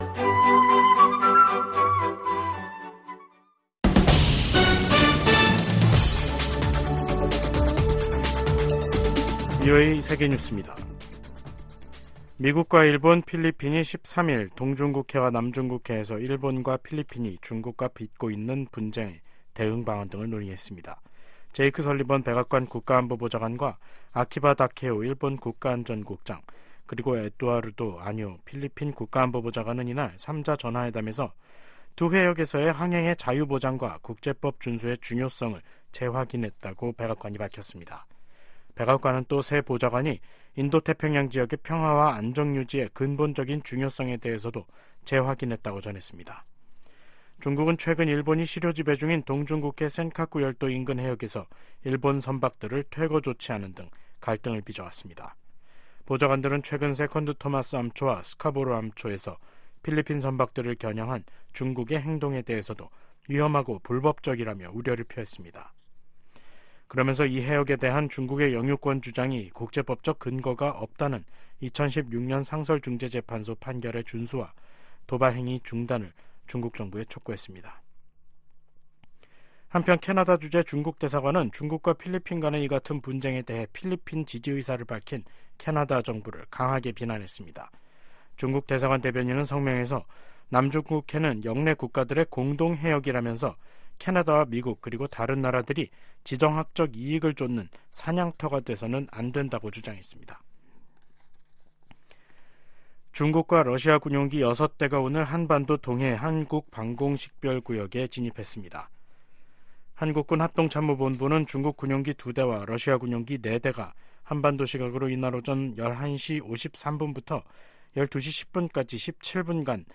VOA 한국어 간판 뉴스 프로그램 '뉴스 투데이', 2023년 12월 14일 3부 방송입니다. 미국 정부가 북한 노동자들의 러시아 파견 정황에 대해 북러 협력 문제의 심각성을 지적했습니다. 미국 법무부 고위 당국자가 북한을 미국 안보와 경제적 이익에 대한 위협 가운데 하나로 지목했습니다. 네덜란드가 윤석열 한국 대통령의 국빈 방문을 맞아 북한의 미사일 발사를 비판하며 핵실험 자제를 촉구했습니다.